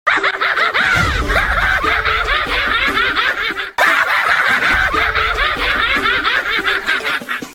The AHAHAHA sound button is from our meme soundboard library